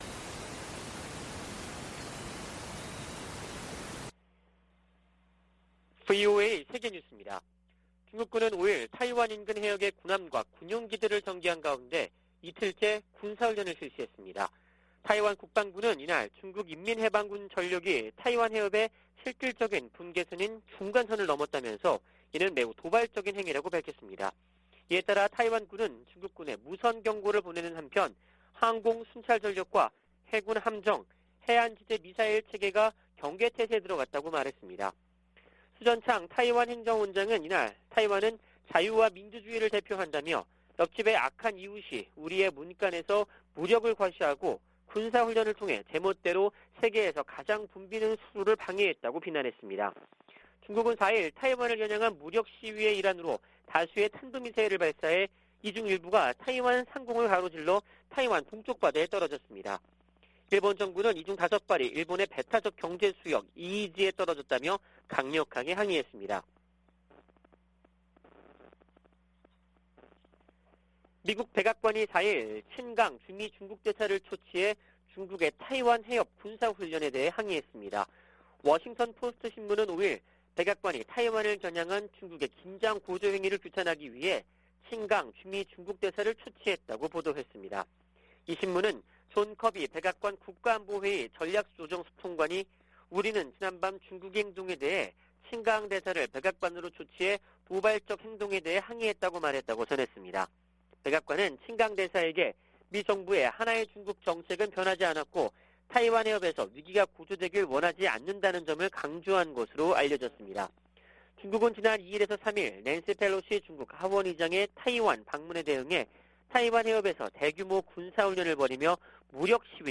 VOA 한국어 '출발 뉴스 쇼', 2022년 8월 6일 방송입니다. 토니 블링컨 미 국무장관은 타이완 해협에서 무력으로 변화를 노리는 어떠한 시도에도 반대한다고 말했습니다.